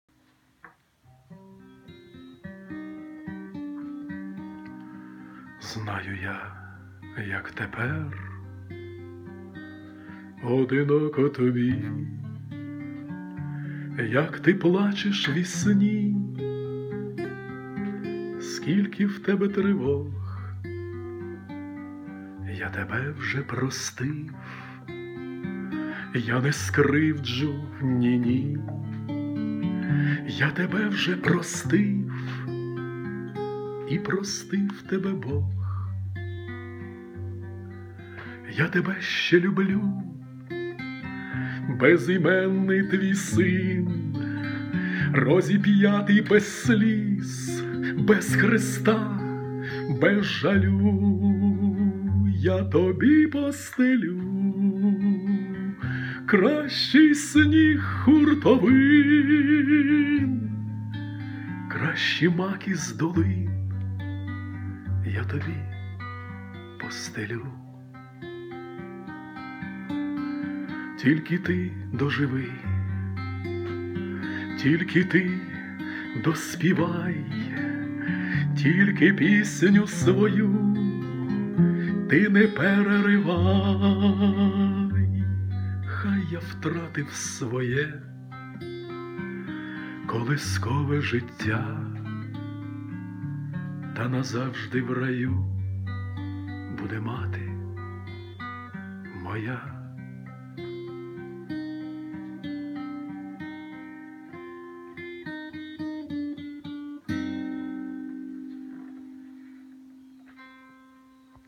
Авторська пісня